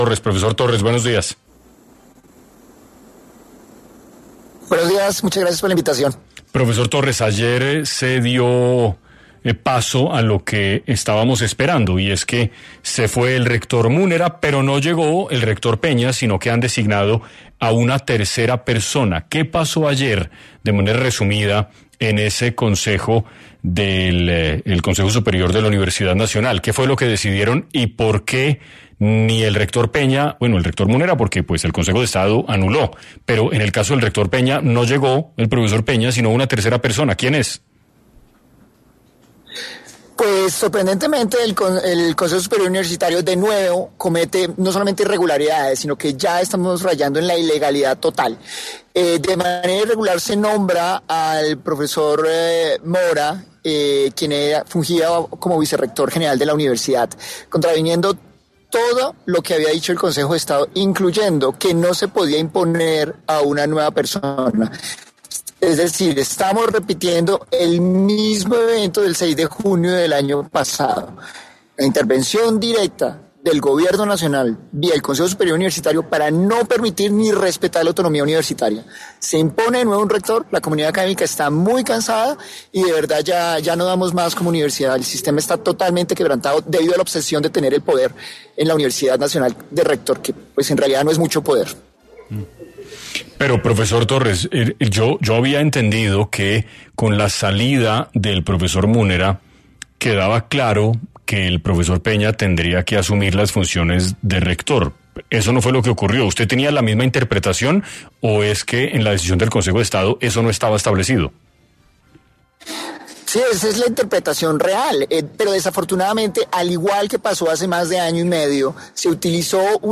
En entrevista con 6AM